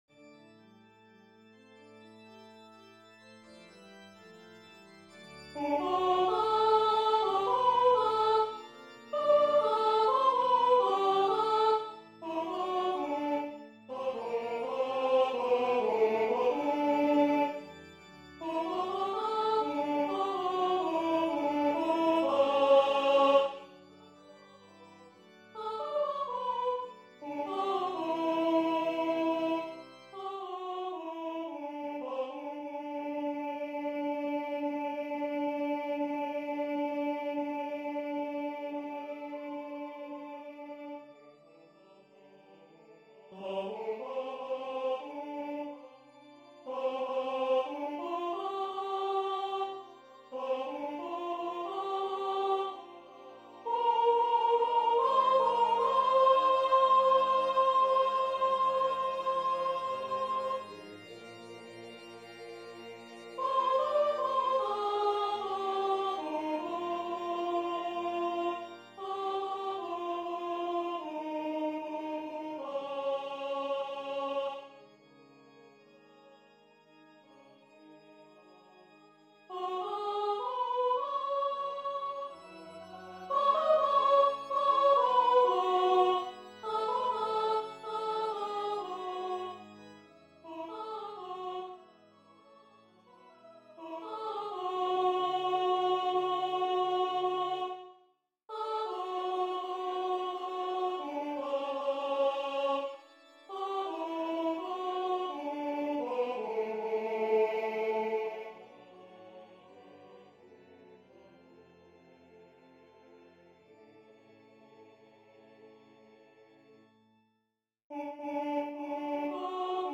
For each part there are two versions - the first has just that particular voice part playing, the other has it with the other voices playing quietly in the background.
Alto 1
af_alto1_others_quiet.mp3